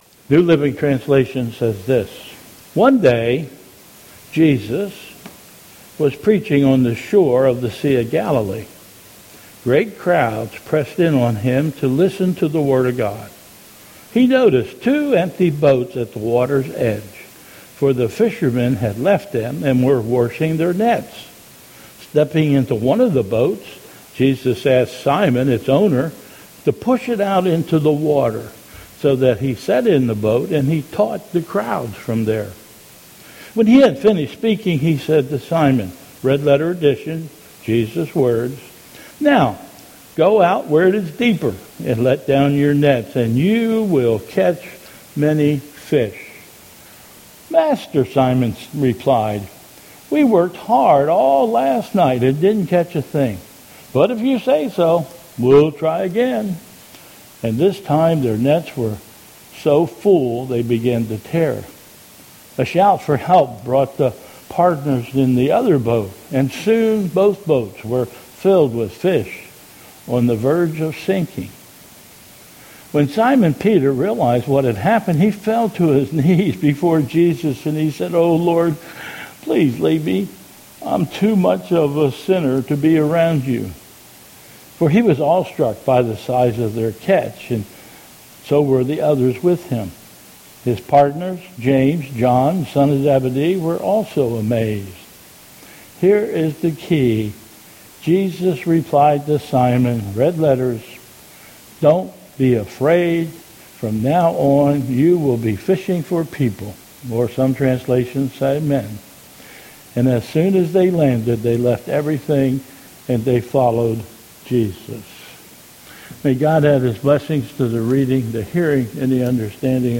2022 Bethel Covid Time Service
Introduction to the Message Scripture